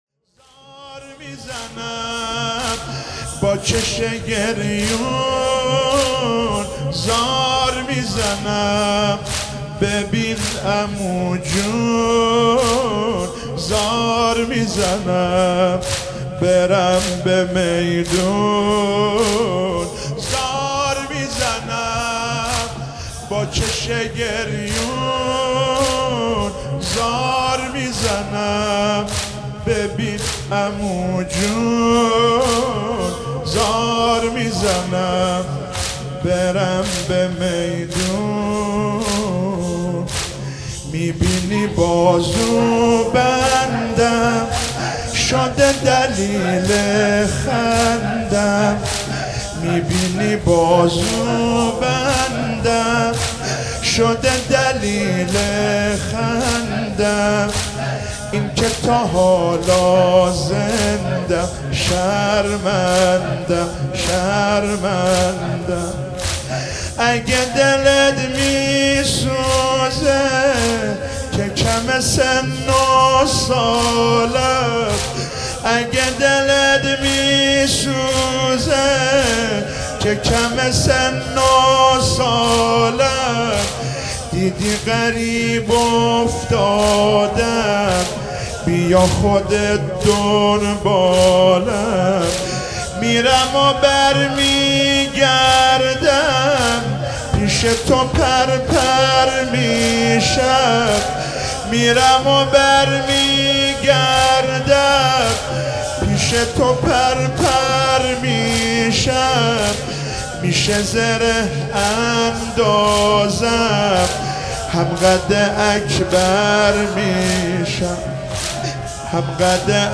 شب ششم محرم96 - زمینه - زار میزنم با چش گریون